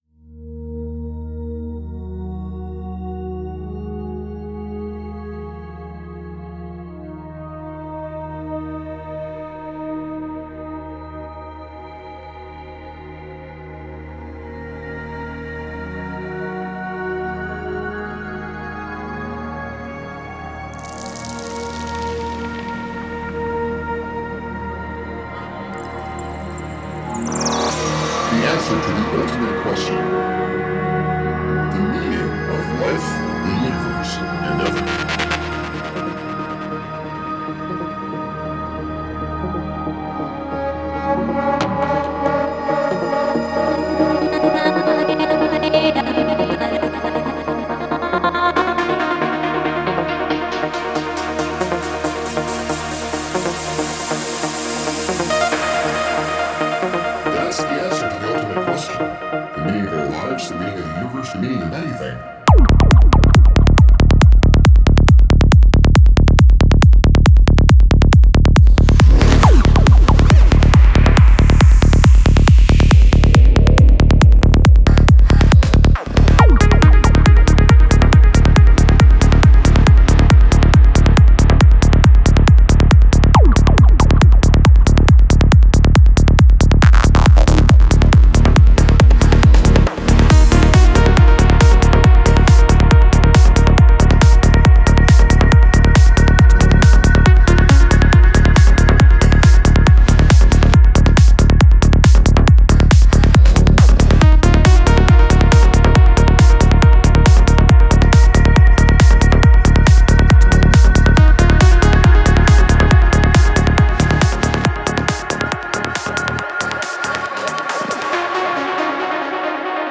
Файл в обменнике2 Myзыкa->Psy-trance, Full-on
Стиль: Psy Trance